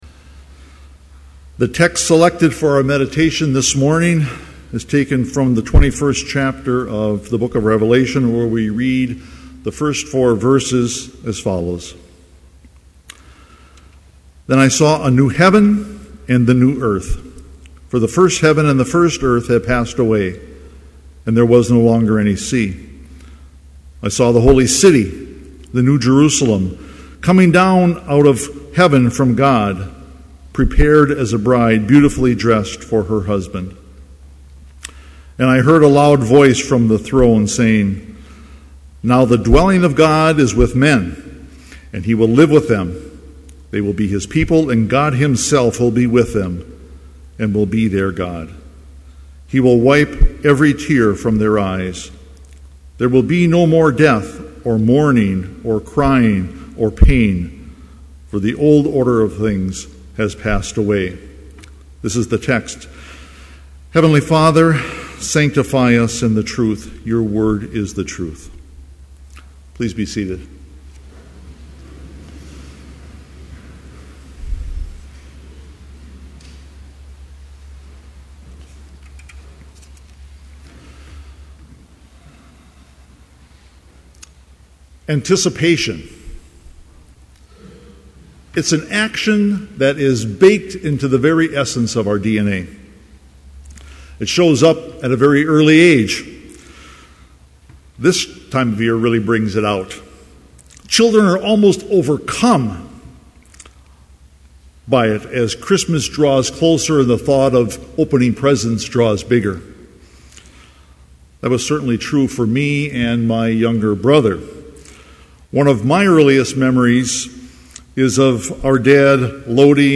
Complete Service
• Homily
• Postlude – Chapel Brass
This Chapel Service was held in Trinity Chapel at Bethany Lutheran College on Friday, December 9, 2011, at 10 a.m. Page and hymn numbers are from the Evangelical Lutheran Hymnary.